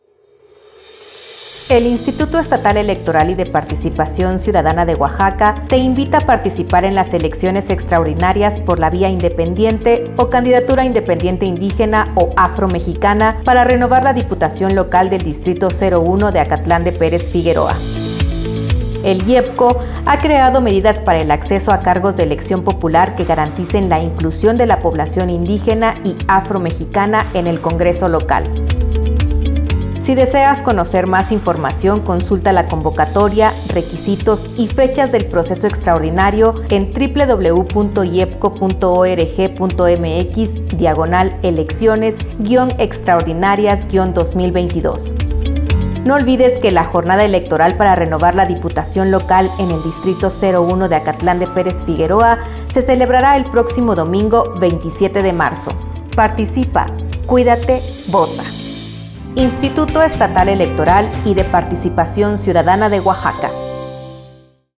Spot Diputación Local Dtto. 01